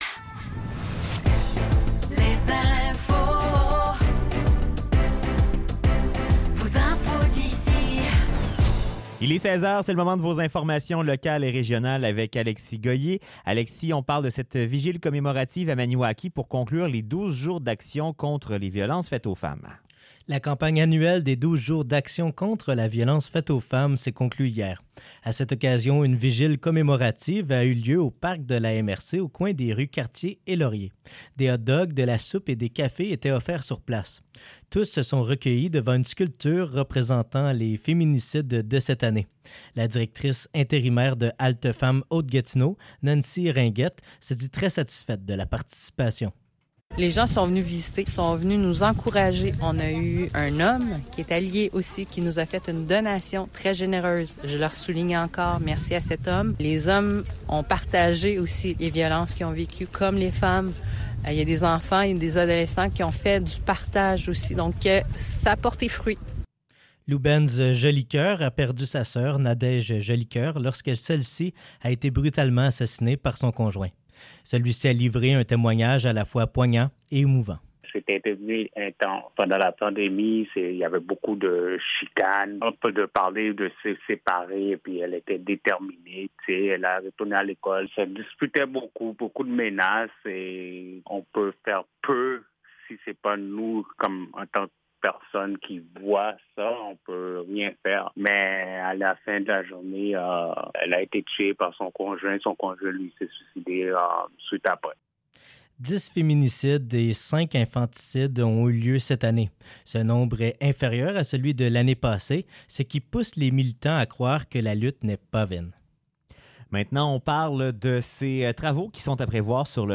Nouvelles locales - 7 décembre 2023 - 16 h